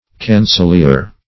Cancelier \Can`cel*ier"\, v. i. [F. chanceler, OF. canseler, to